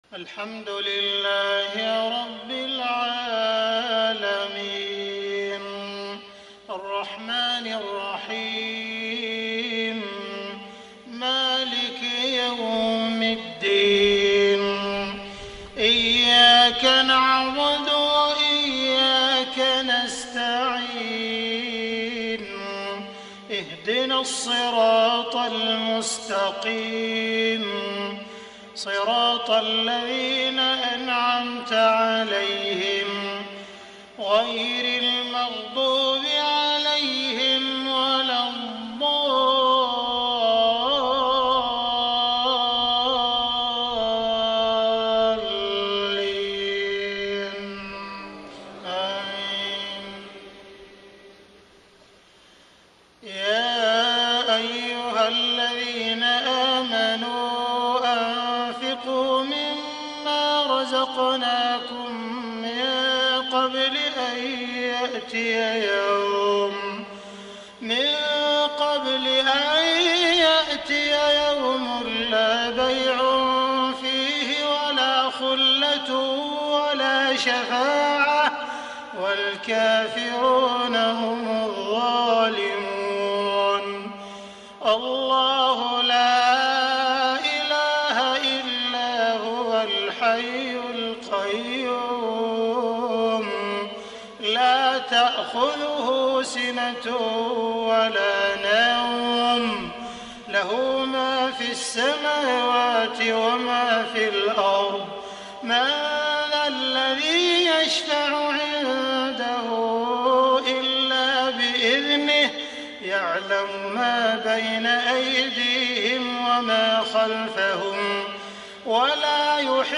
صلاة المغرب ٦ محرم ١٤٣٤هـ من سورة البقرة | > 1434 🕋 > الفروض - تلاوات الحرمين